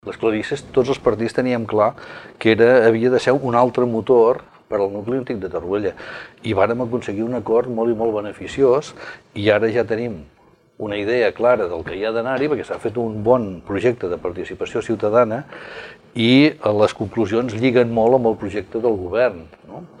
Debat Electoral Torroella de Montgrí - l'Estartit 2019
Un dia en què l’agenda vindrà marcada per les propostes que es van llançar ahir des de l’estudi a l’hotel Mas de Torrent des d’on cada vespre emetem un dels nostres debats.